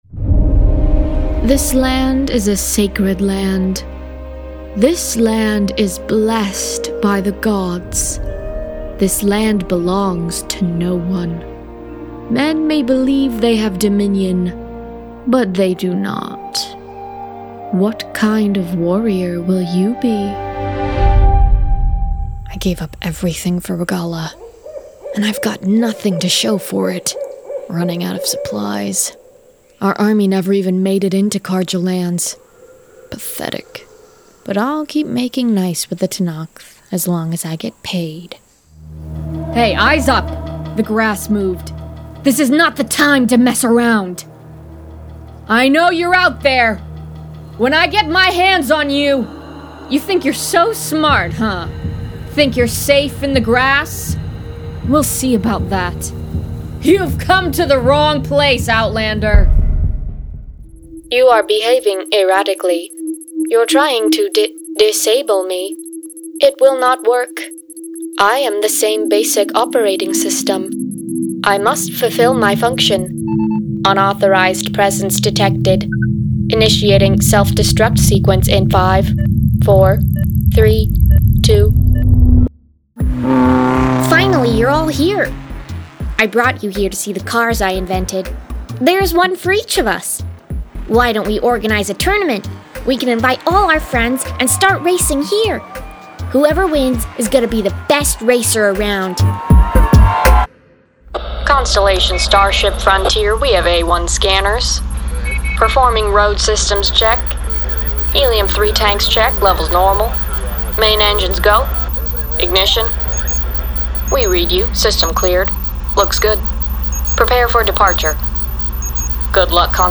Video Game Reel
• Native Accent: Californian
• Home Studio